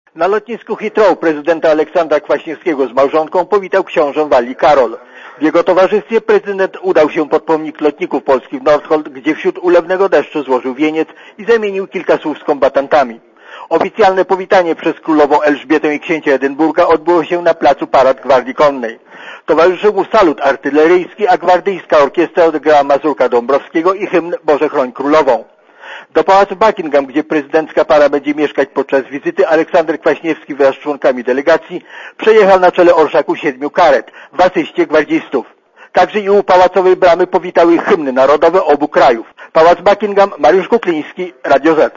Koresponencja z Londynu